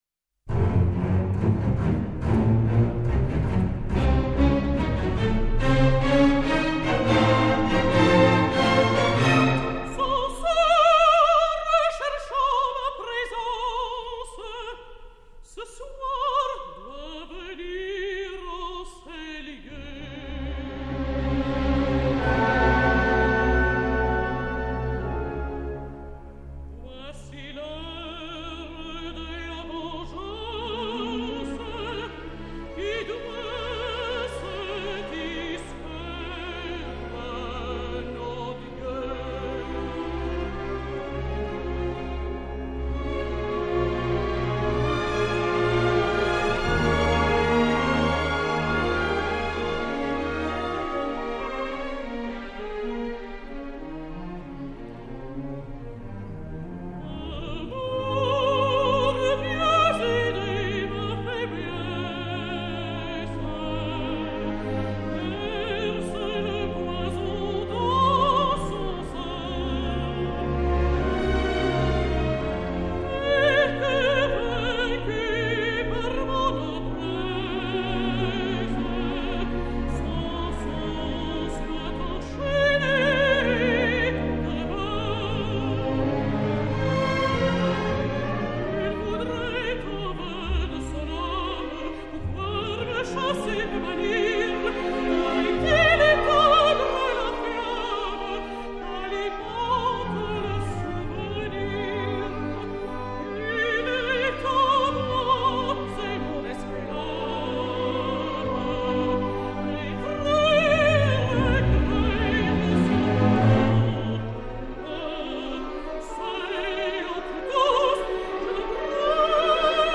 mezzo-soprano belge